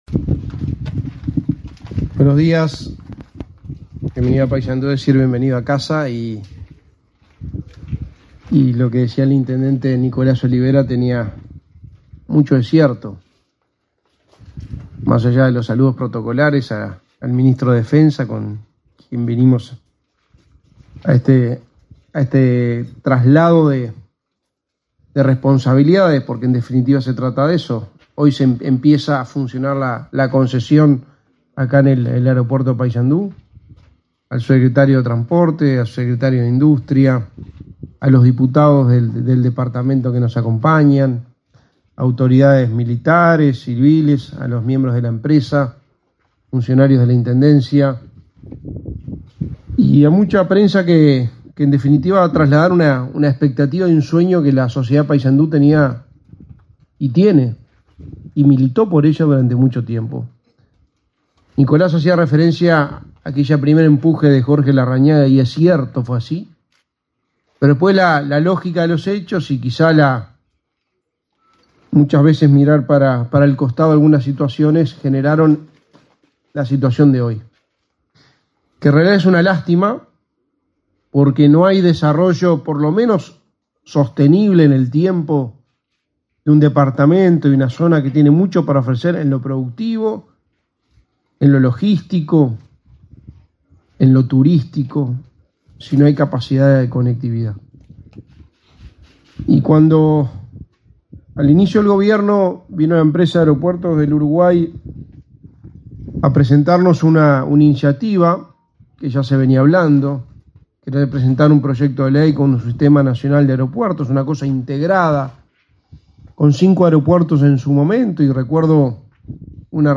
Conferencia de prensa por traspaso de la gestión del aeropuerto internacional de Paysandú
Conferencia de prensa por traspaso de la gestión del aeropuerto internacional de Paysandú 08/02/2023 Compartir Facebook X Copiar enlace WhatsApp LinkedIn El Ministerio de Defensa Nacional y Aeropuertos Uruguay celebraron, este 8 de febrero, el acto de traspaso de la gestión del aeropuerto internacional de Paysandú. Participaron en el evento el secretario de la Presidencia, Álvaro Delgado, y el ministro de Defensa Nacional, Javier García.